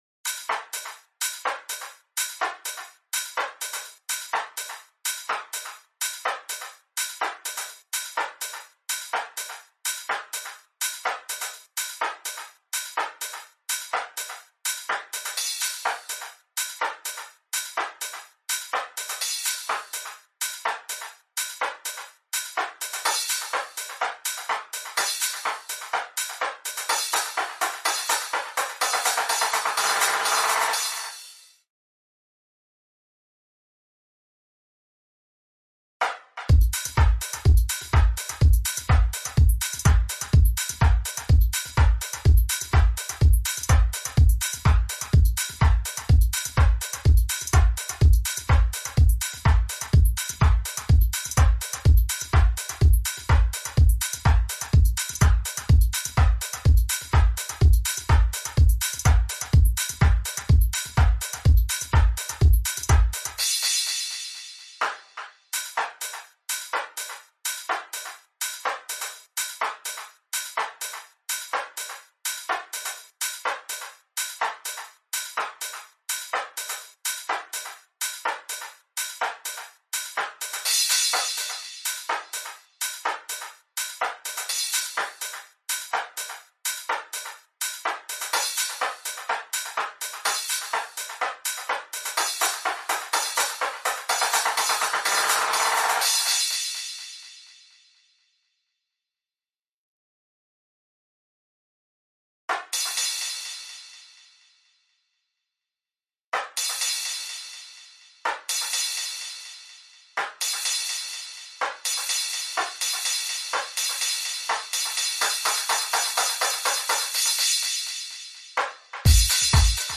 • Both Reverb and Delay were used to make the drum parts sound cleaner, and to give the drum parts an overall nicer sound.
Here is a (compressed) bounce of all the drum parts with effects on:
Drums-with-FX-2.mp3